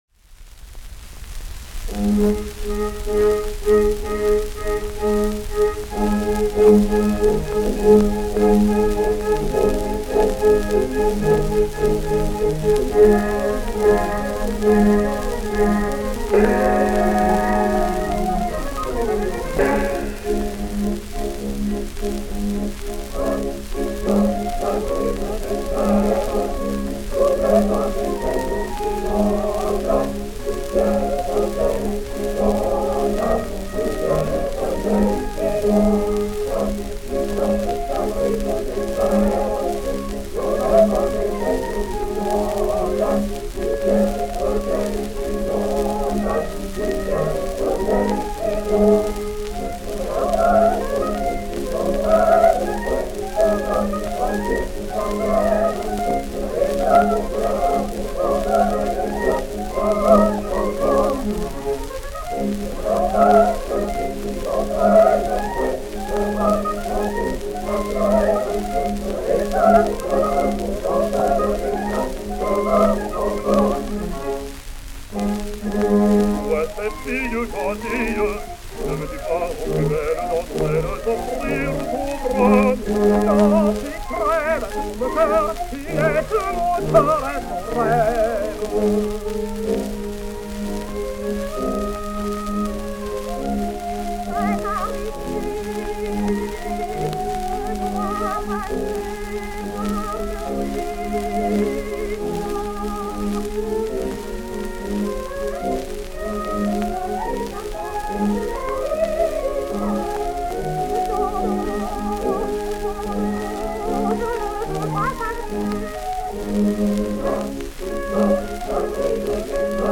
Comprimario tenor.